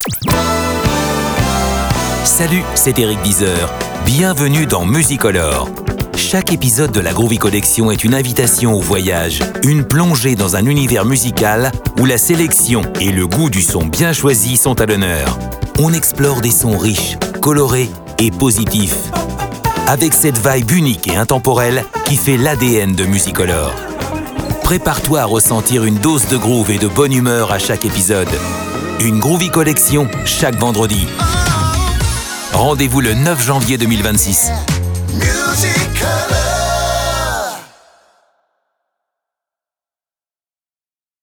Soul Music, Nu Soul, Jazz-Funk, Westcoast, Brazil, Groove, Disco, Nu Disco, Funk, Nu Funk, Funk & Boogie, R&B, Gospel…
On explore des sons riches, colorés et positifs, en mettant en lumière les pépites musicales, sans jamais oublier cette vibe unique et intemporelle.
BANDE ANNONCE